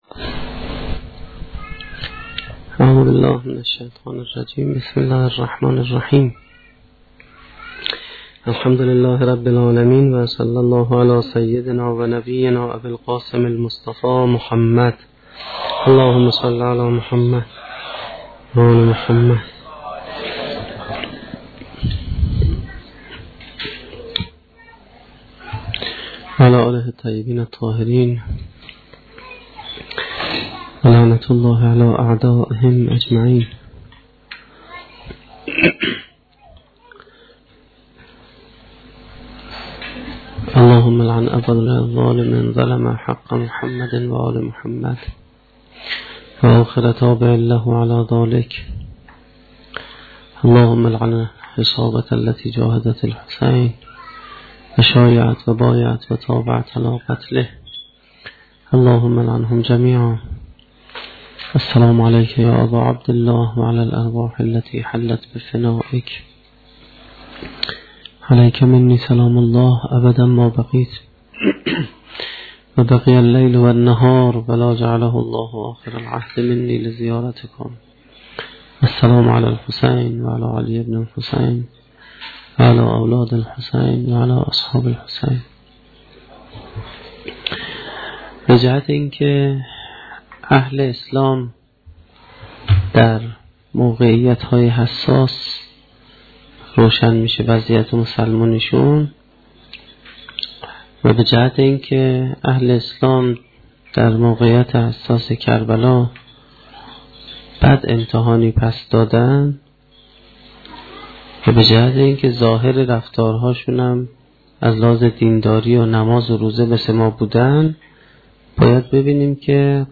سخنرانی چهارمین شب دهه محرم1435-1392